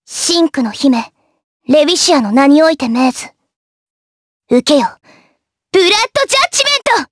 Lewsia_A-Vox_Skill7_jp.wav